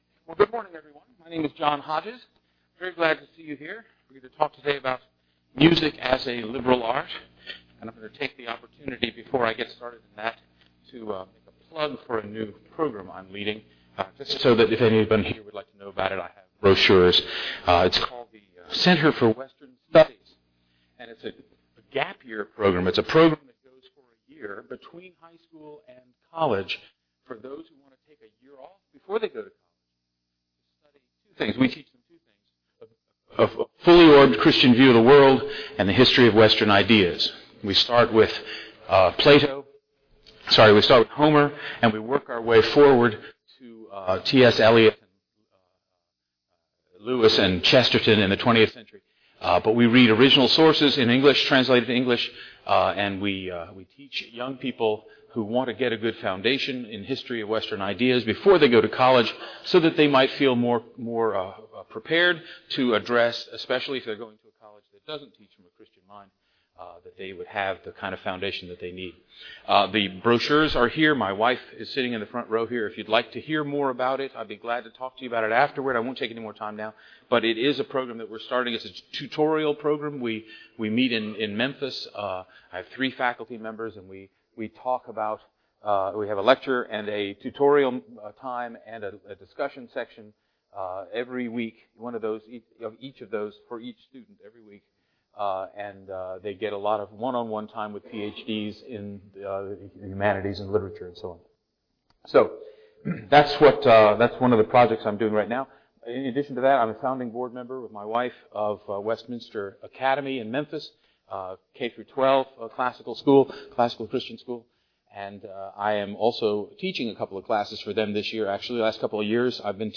2009 Workshop Talk | 1:00:35 | All Grade Levels, Art & Music